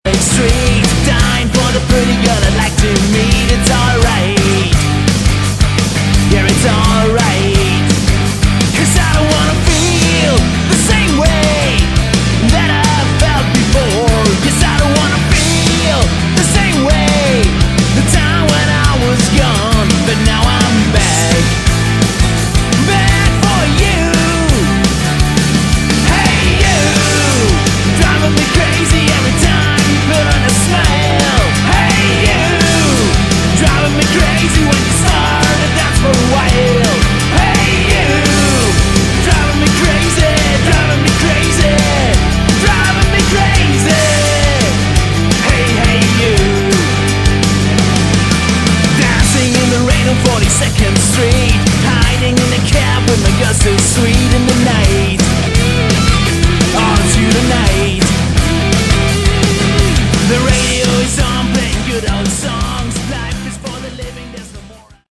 Category: Hard Rock
vocals, guitar, bass and keyboards
drums